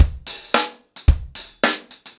DaveAndMe-110BPM.13.wav